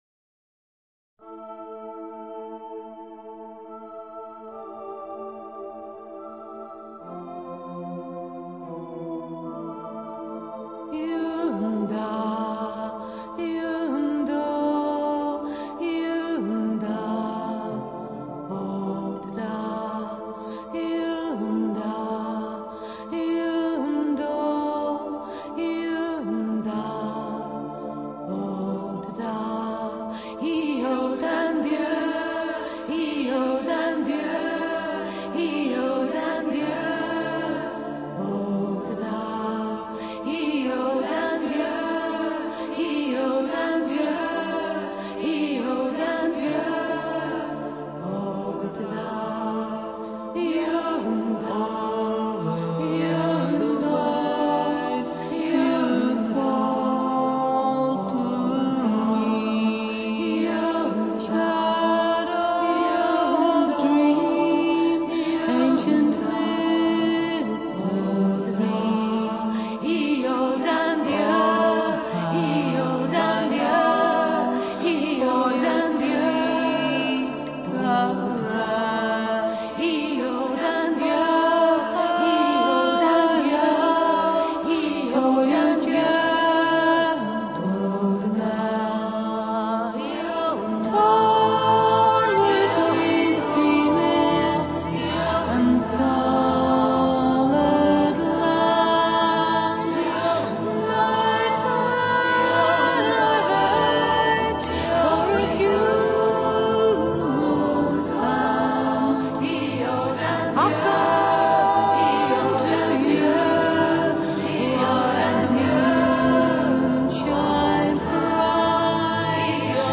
striking lament